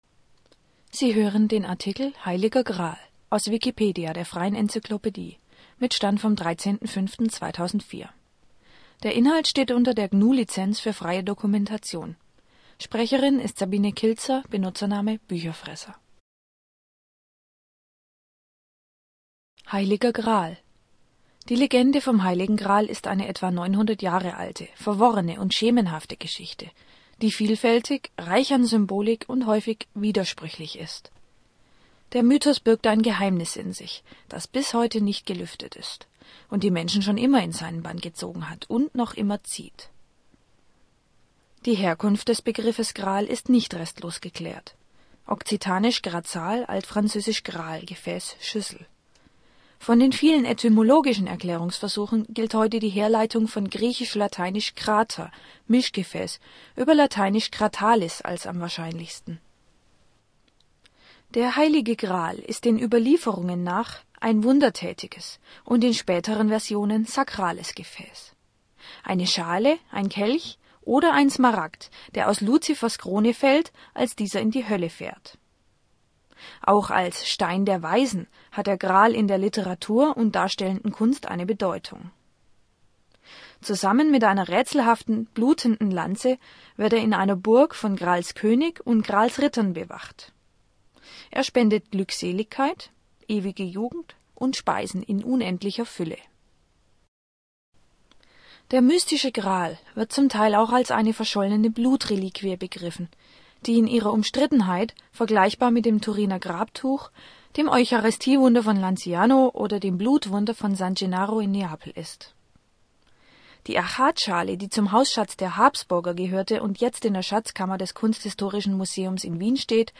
Lektüre